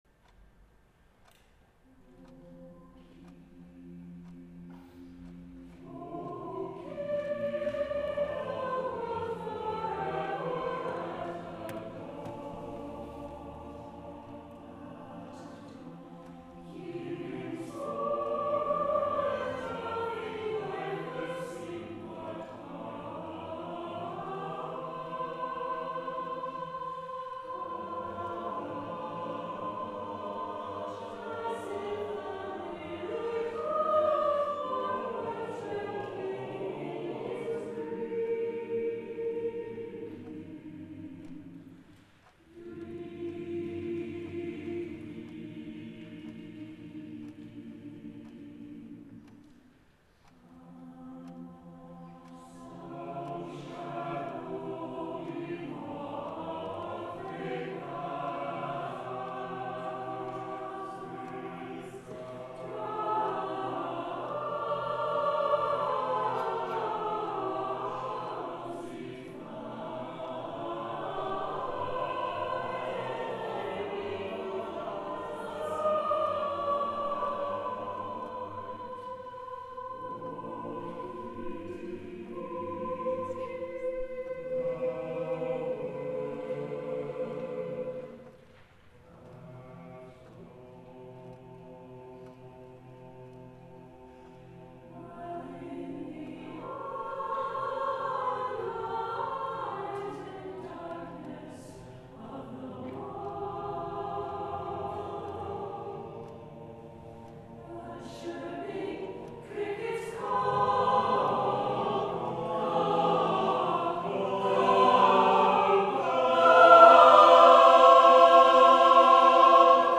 SATB a cappella